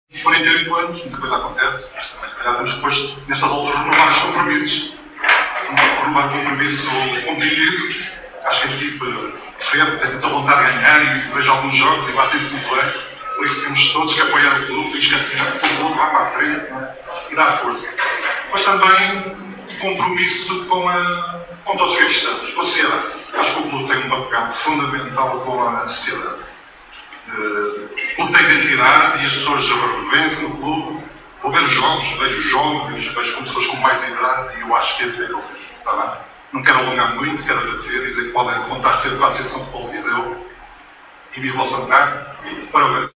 Este domingo, 27 de julho, realizou-se na sede do Sporting Clube de Santar o almoço do 48º aniversário, onde reuniu Associados, Dirigentes, Representante da Associação de Futebol de Viseu, Presidente da União de Freguesias de Santar e Moreira e Presidente da Câmara Municipal de Nelas.